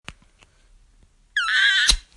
short-squeak-39519.mp3